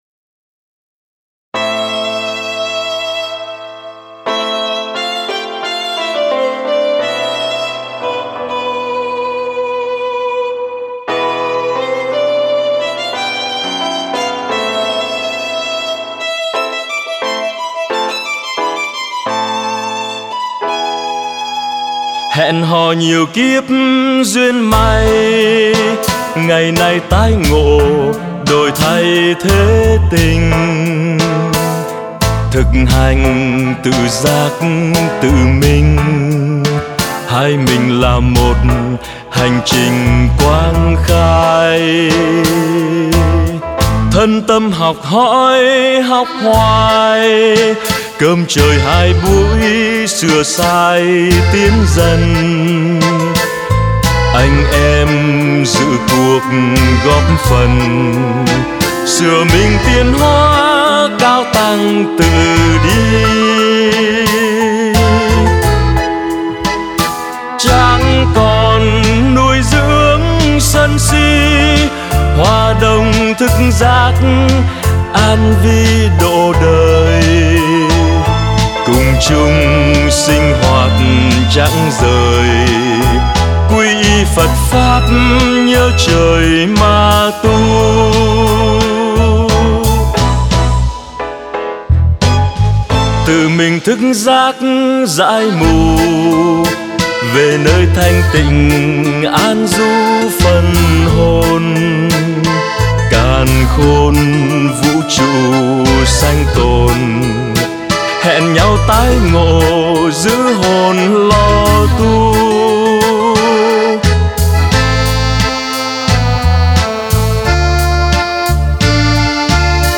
Tân Nhạc